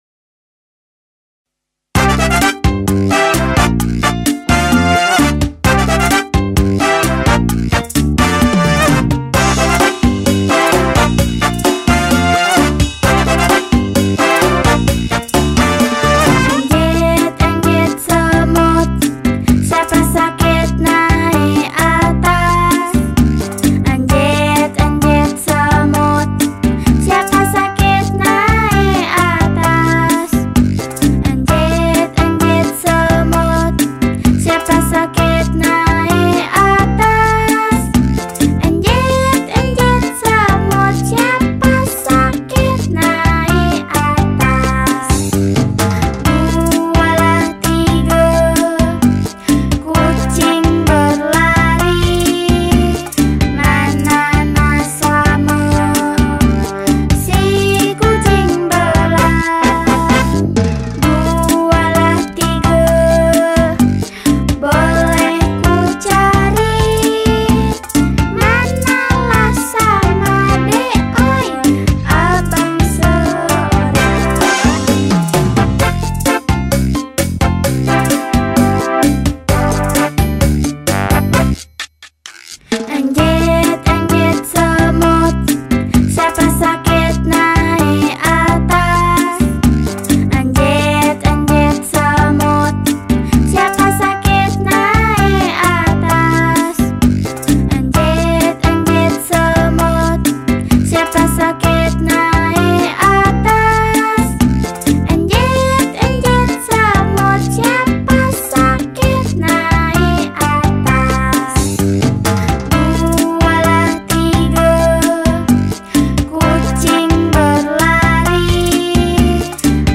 Malay Children Song